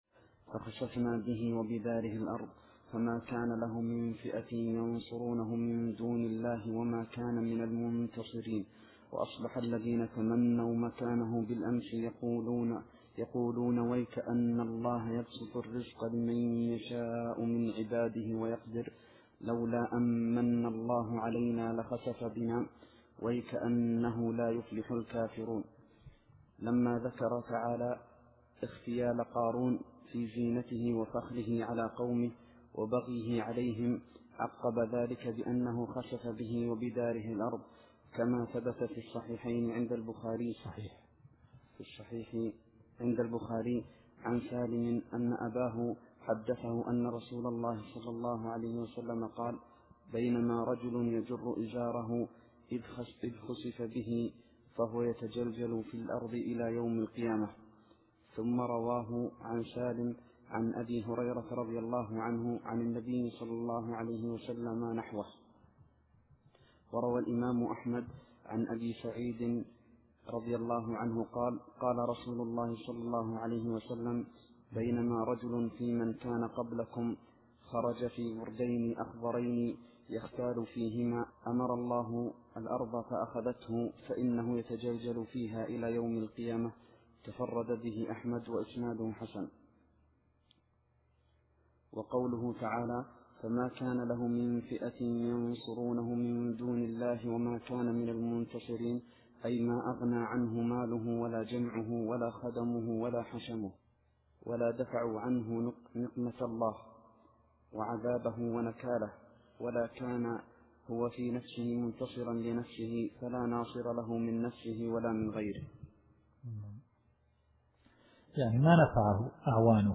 التفسير الصوتي [القصص / 81]